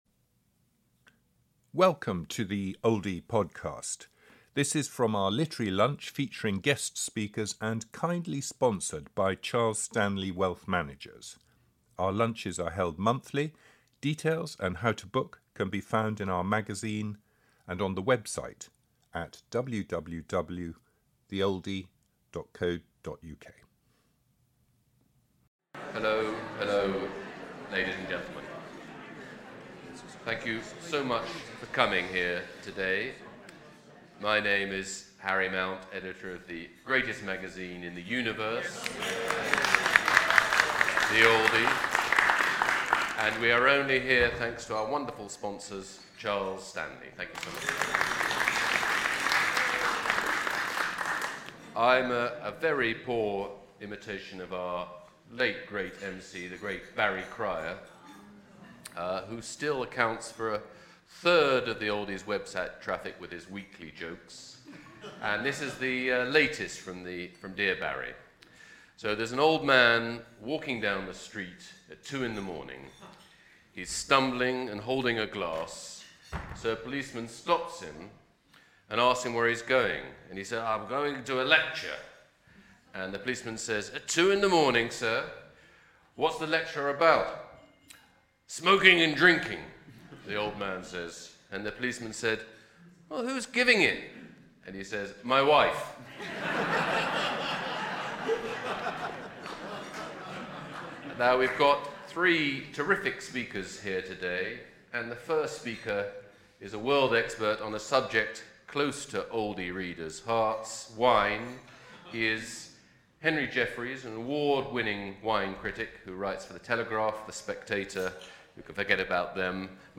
at The Oldie Literary Lunch